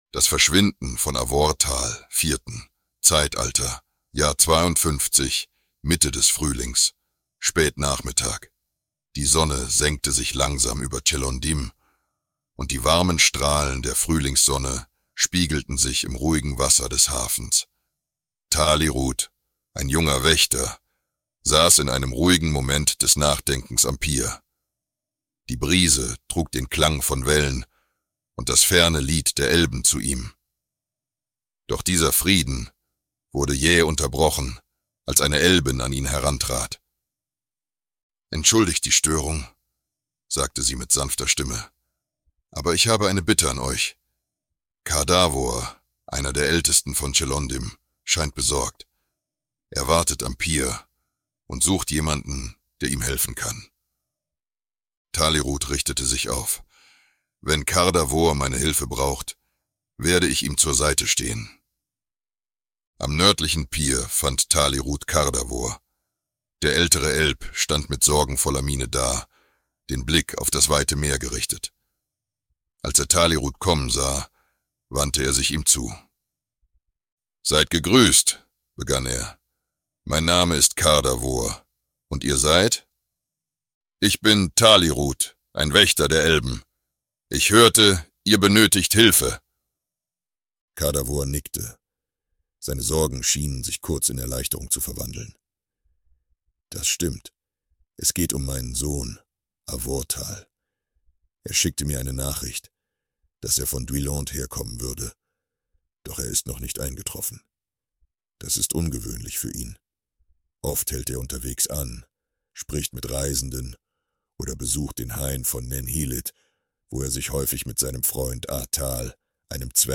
KI-basierte Erzähler bringt euch regelmäßig spannende Geschichten